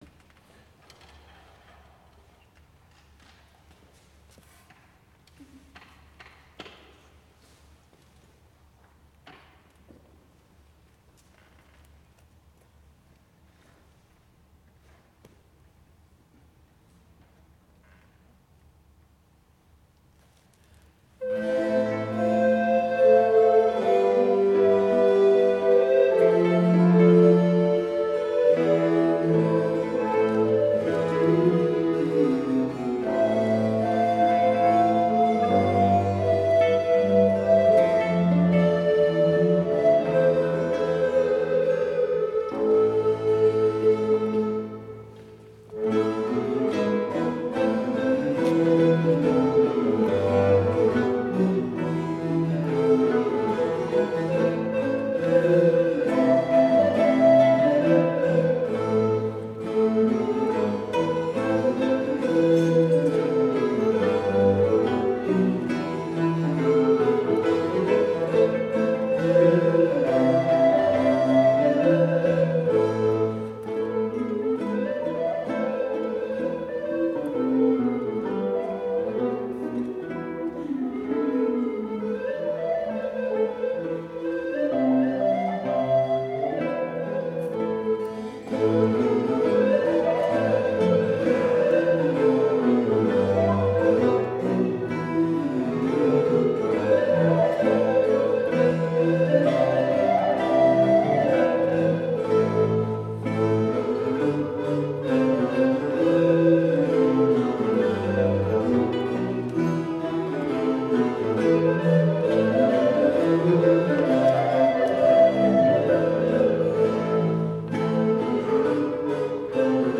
Ungdomsblokkfløytistene Uranienborg.
Fra en konsert Gamle Aker kirke, Oslo 12. desember 2008.